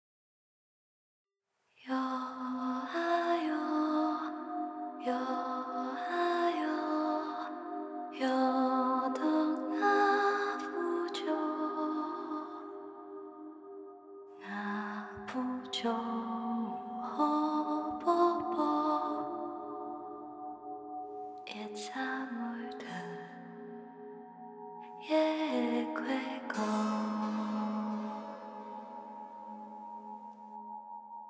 BGM